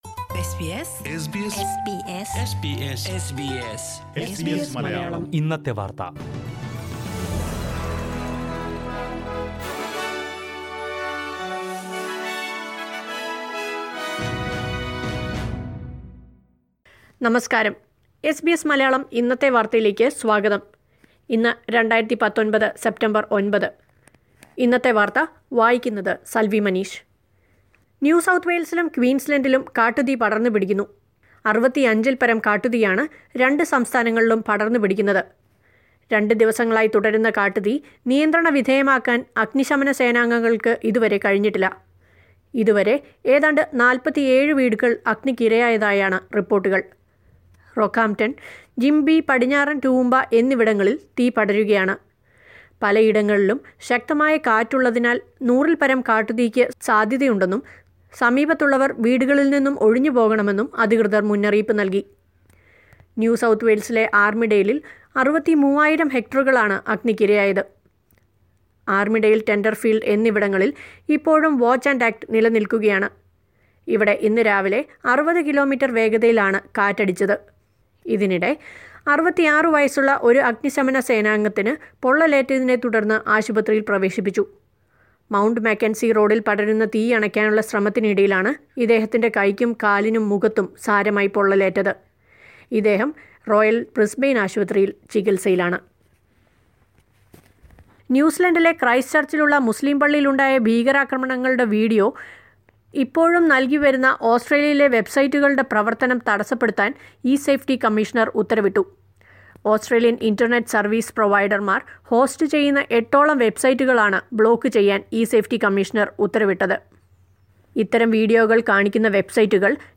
news_sep_9.mp3